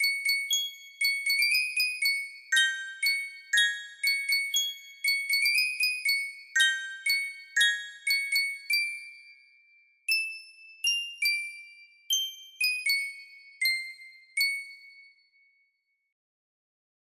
my grandfathers clock music box melody
Full range 60